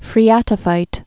(frē-ătə-fīt)